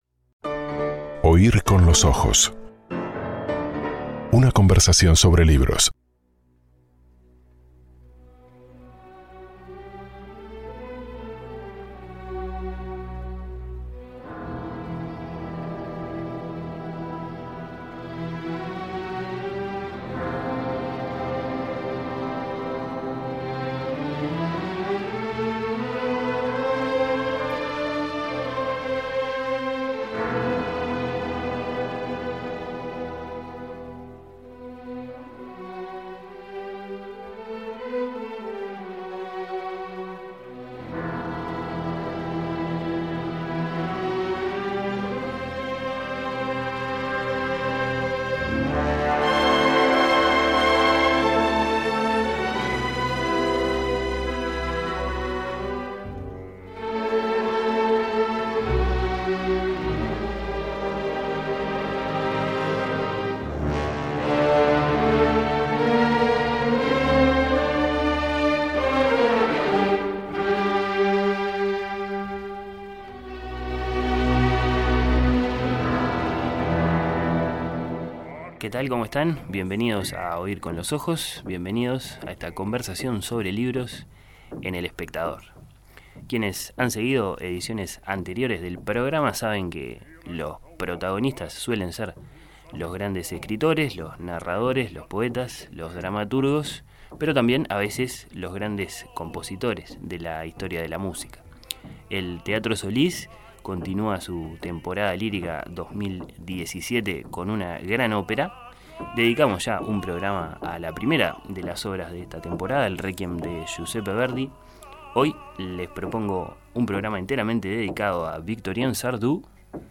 Programa